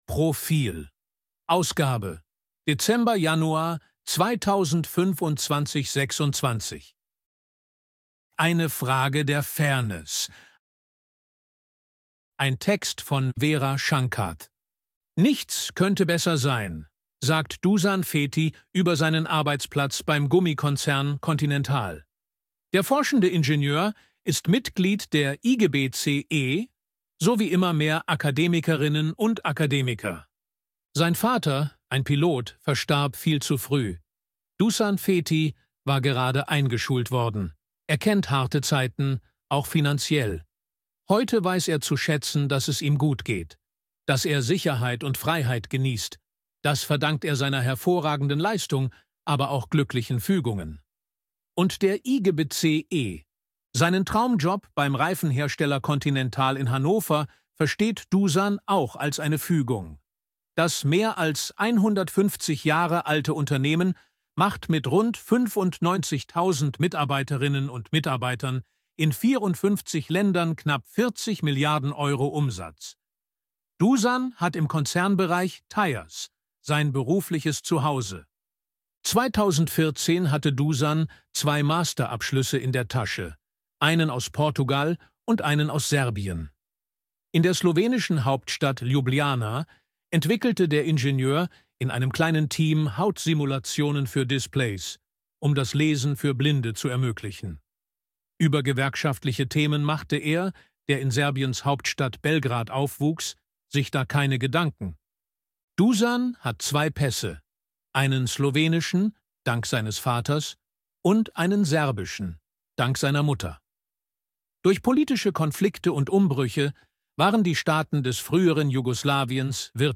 Artikel von KI vorlesen lassen ▶ Audio abspielen
ElevenLabs_256_KI_Stimme_Mann_Portrait.ogg